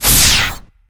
alien_bellow_03.ogg